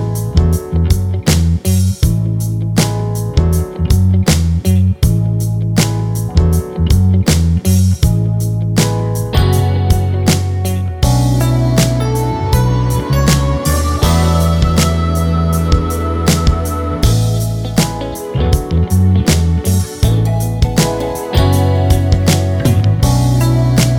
Duet Version Duets 3:06 Buy £1.50